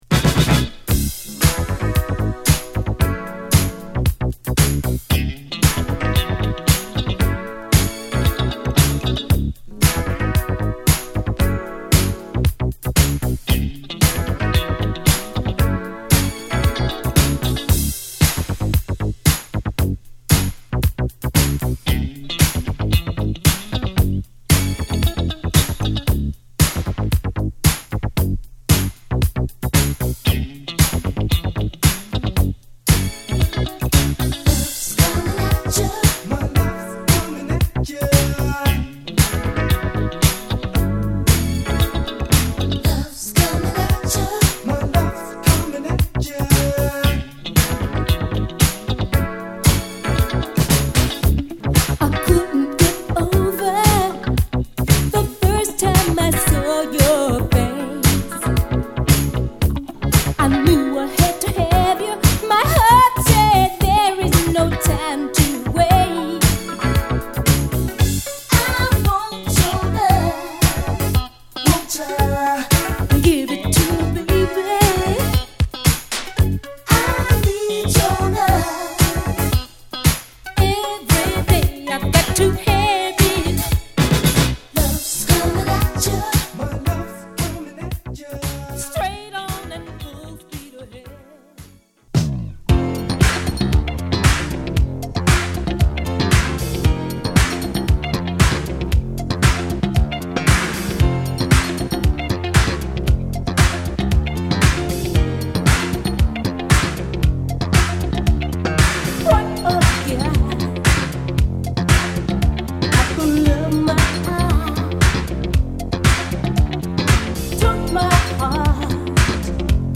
洗練されたNYスタイルのダンスチューンを満載！